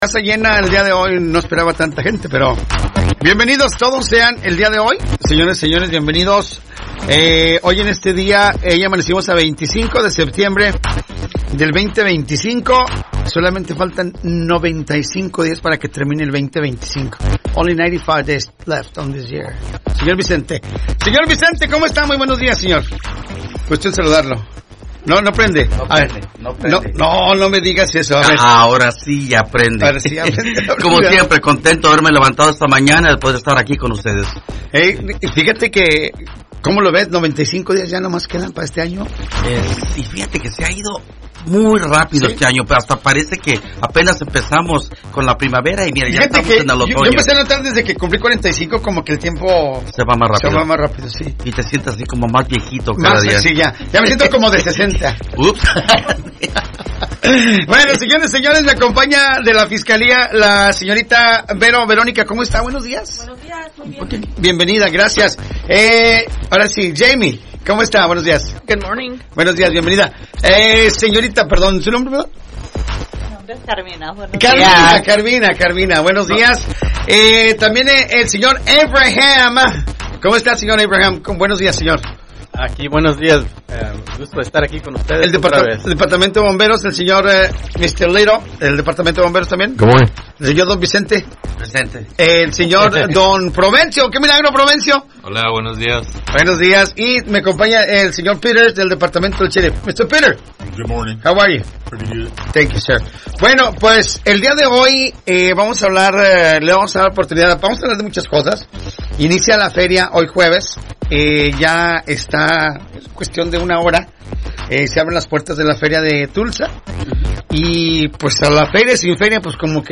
En esta edición los oficiales compartieron información clave para la comunidad: